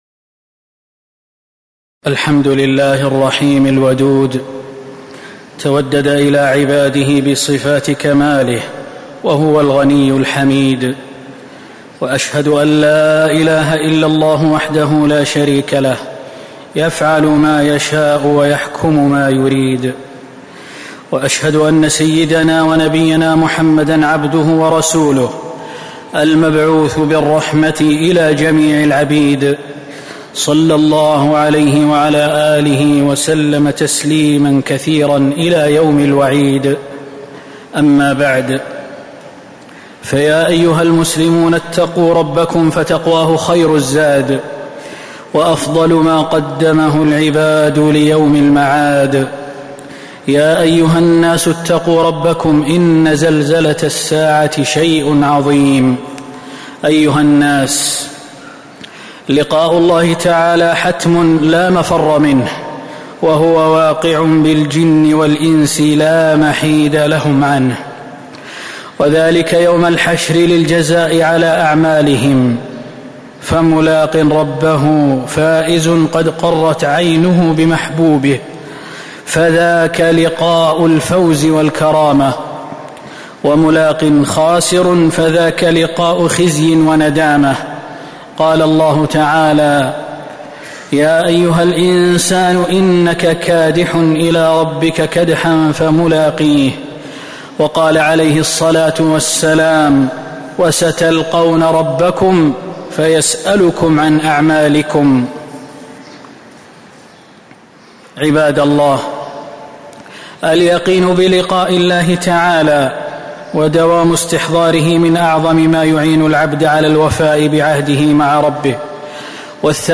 تاريخ النشر ١٢ صفر ١٤٤٦ هـ المكان: المسجد النبوي الشيخ: فضيلة الشيخ د. خالد بن سليمان المهنا فضيلة الشيخ د. خالد بن سليمان المهنا لذة الشوق إلى لقاء الله تعالى